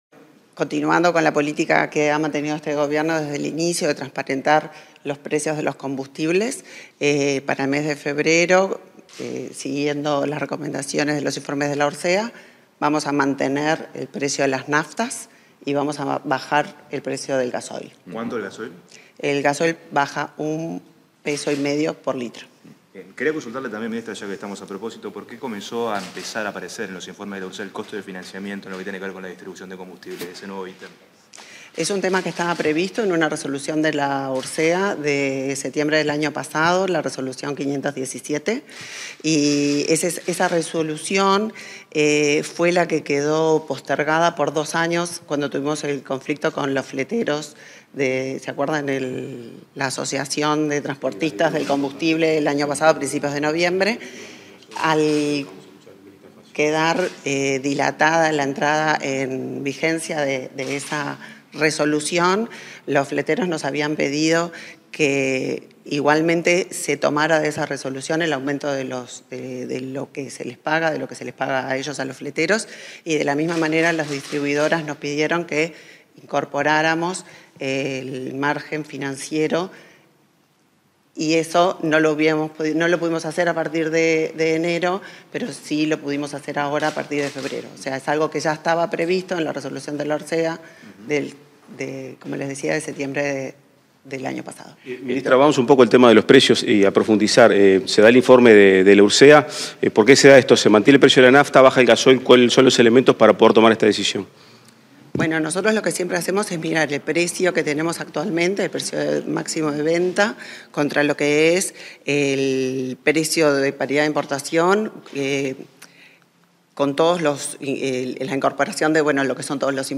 Declaraciones de la ministra de Industria, Energía y Minería, Elisa Facio